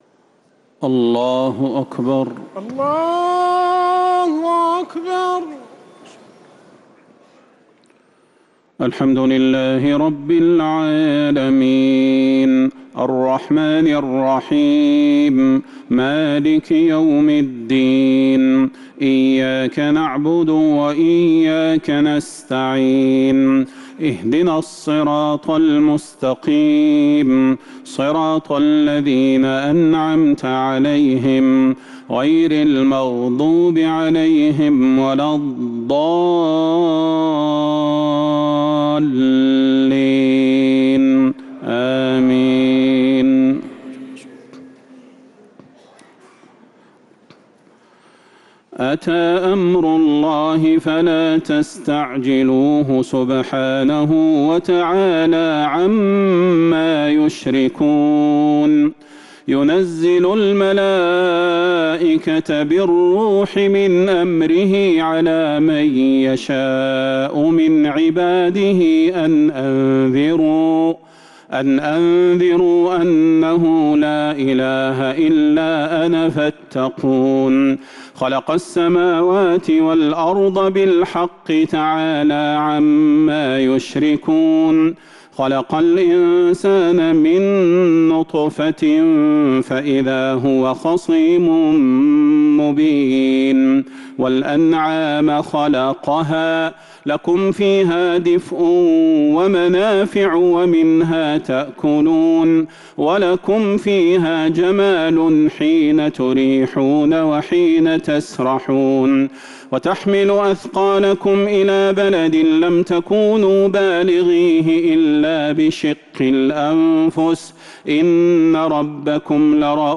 تراويح ليلة 18 رمضان 1447هـ فواتح سورة النحل (1-55) | Taraweeh 18th night Ramadan 1447H Surat An-Nahl > تراويح الحرم النبوي عام 1447 🕌 > التراويح - تلاوات الحرمين